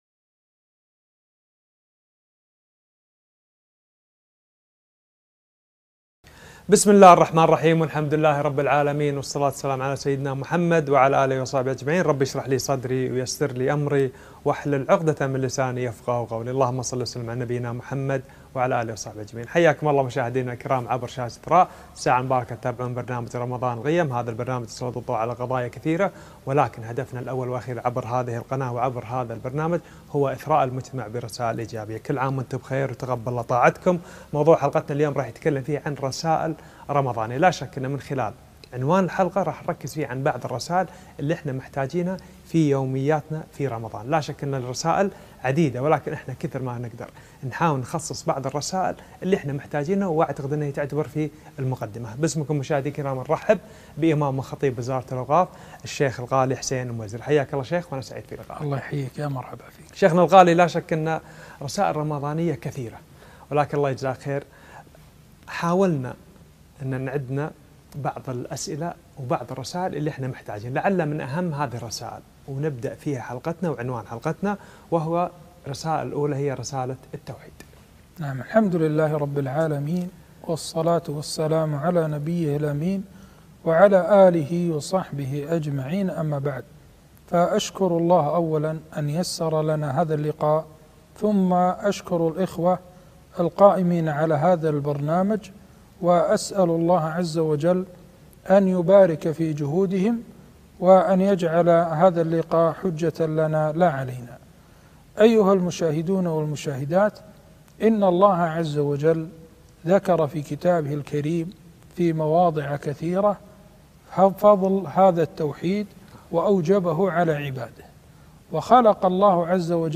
رسائل رمضانية - لقاء على قناة إثراء